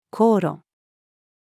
高炉-female.mp3